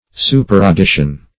superaddition - definition of superaddition - synonyms, pronunciation, spelling from Free Dictionary